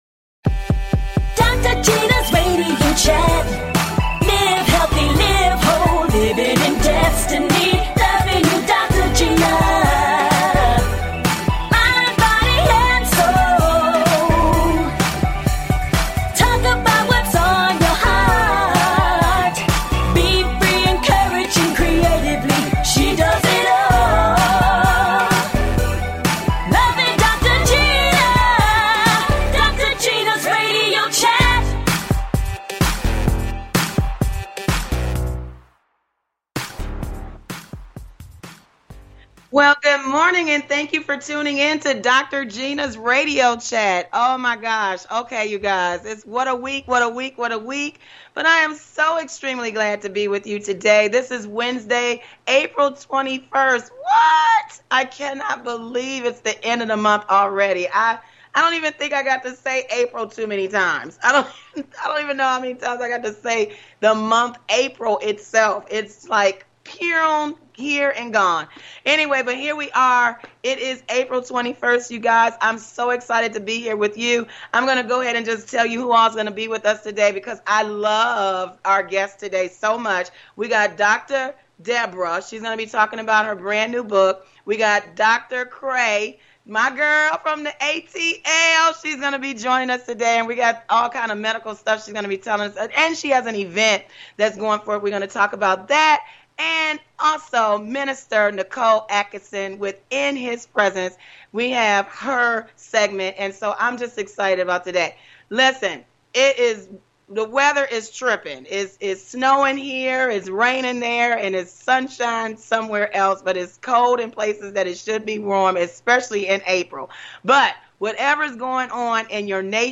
And full of laughter!
A talk show of encouragement.